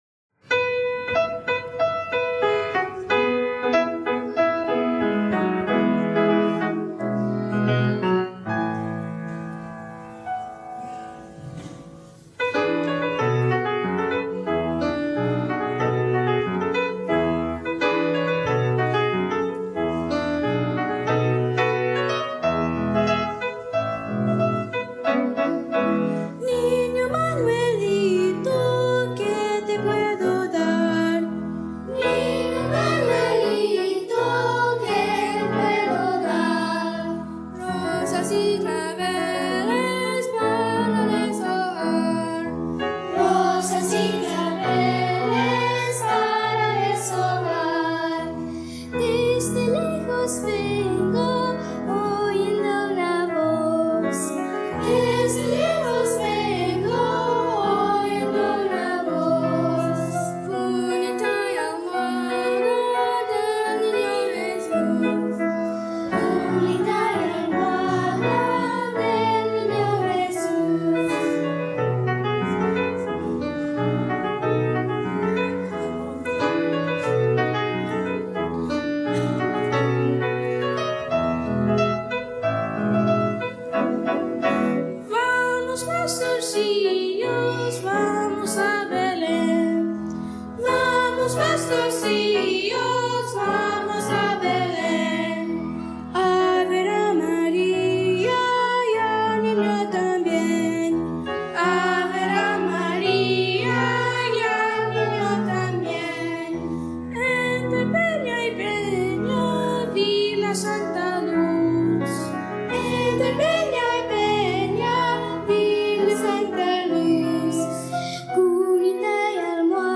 Unison + Piano 2’30”
Unison, Piano